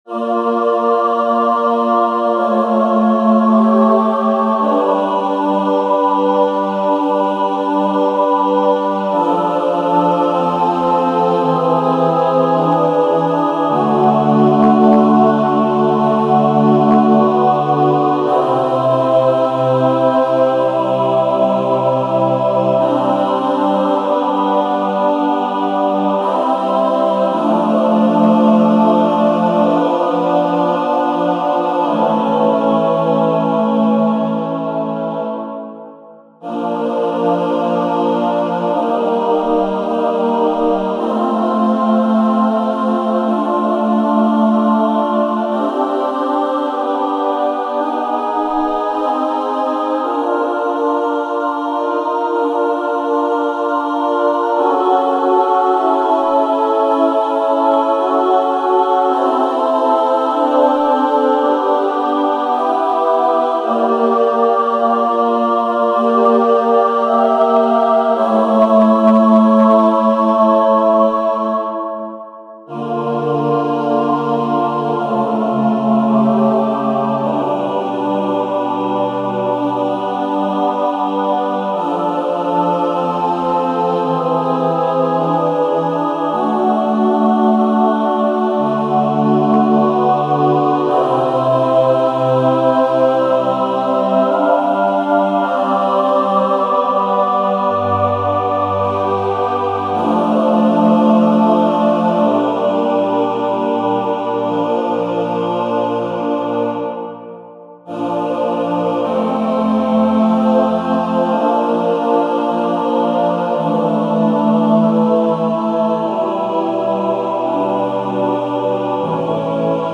Number of voices: 3,4vv Voicings: ATB or SATB Genre: Sacred, Motet
Language: Latin Instruments: A cappella
Locus_iste_ATB.mp3